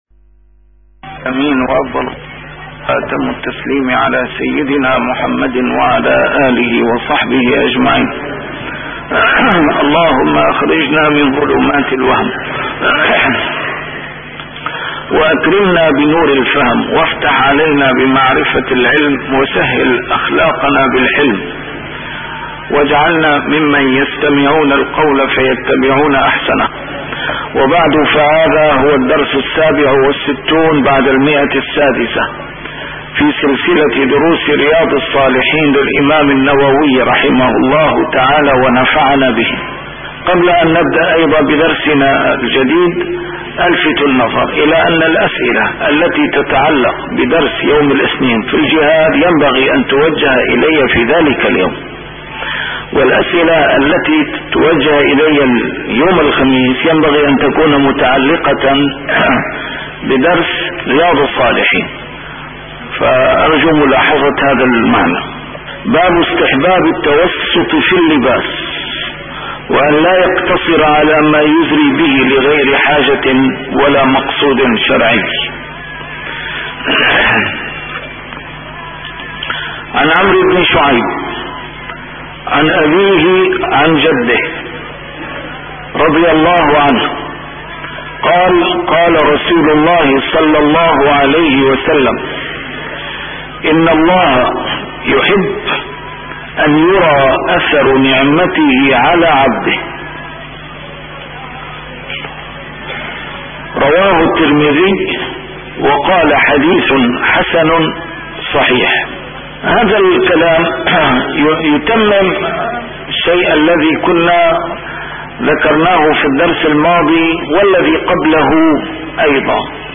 شرح كتاب رياض الصالحين - A MARTYR SCHOLAR: IMAM MUHAMMAD SAEED RAMADAN AL-BOUTI - الدروس العلمية - علوم الحديث الشريف - 667- شرح رياض الصالحين: استحباب التوسط في اللباس